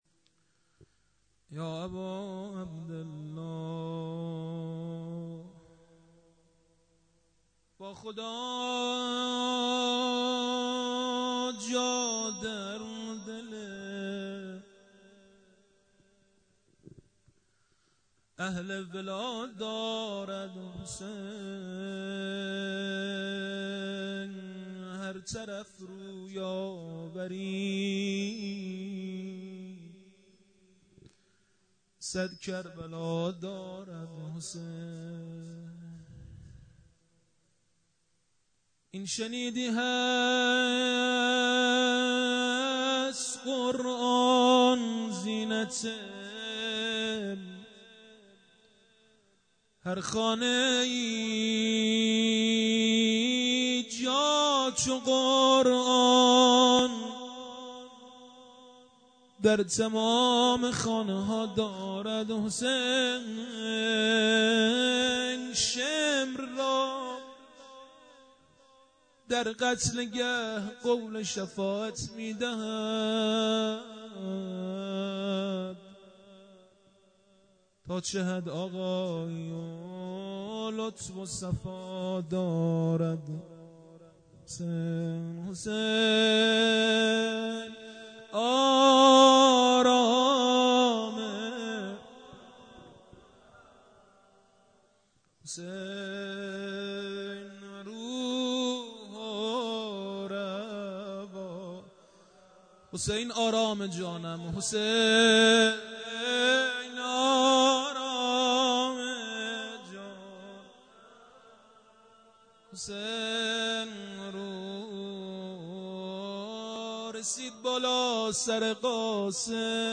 خیمه گاه - هیئت حضرت رقیه س (نازی آباد) - شب ششم مداحی
هیئت حضرت رقیه س (نازی آباد)